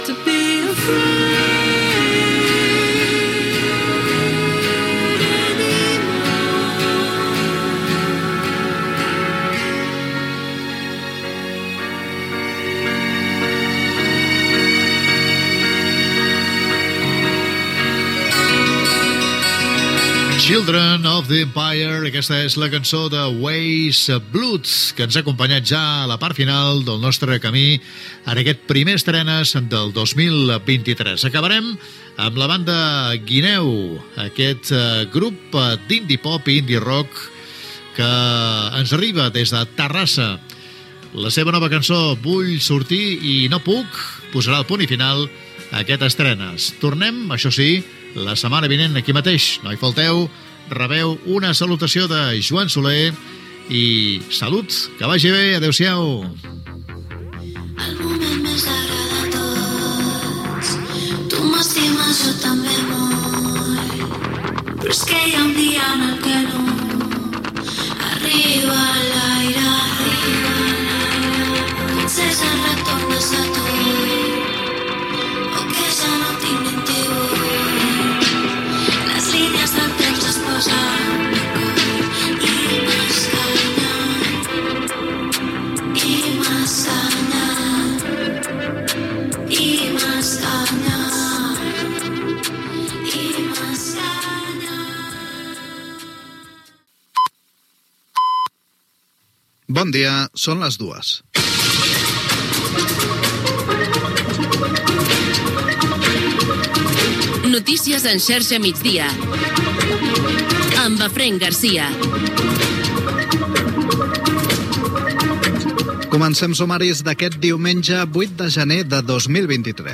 Comiat del programa musical. Hora, careta de l'informatiu. Sumari: operació retorn, primer festiu amb botigues obertes, etc. Informació del temps
Musical
Informatiu